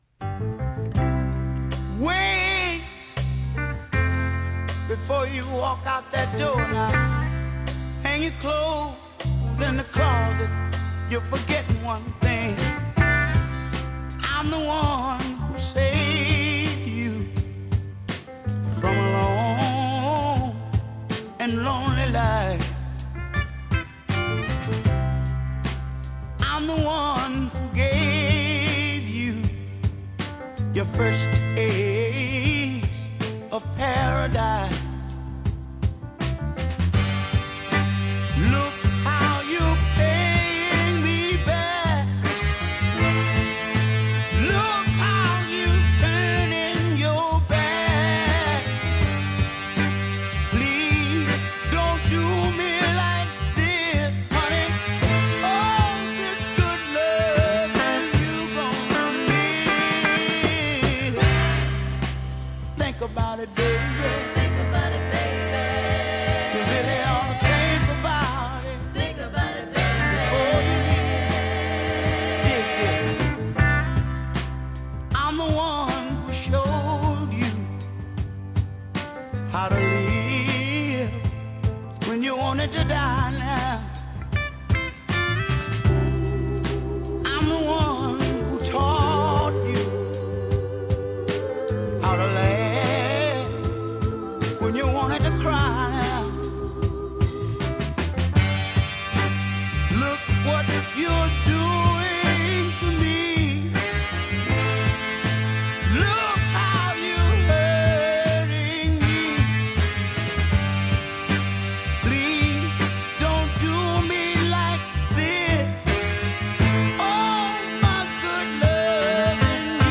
a heartfelt cut